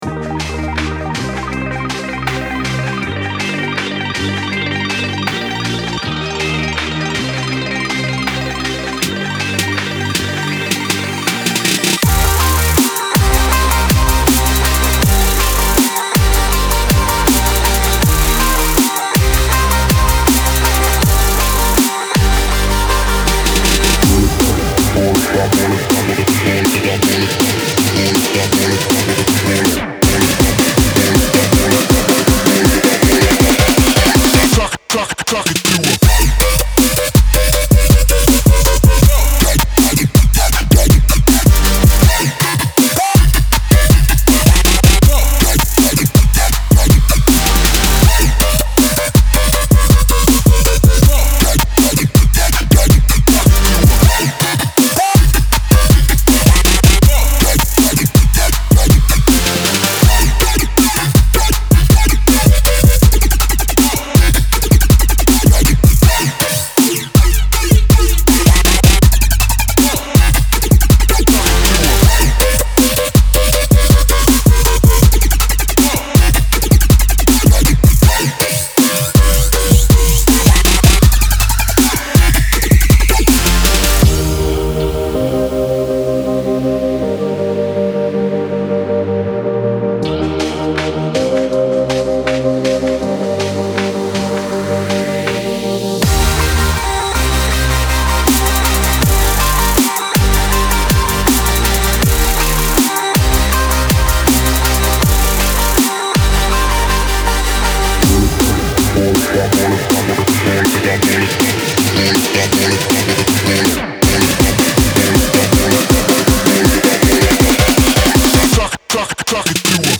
Melodic Dubstep, Angry, Sad, Gloomy, Energetic, Dark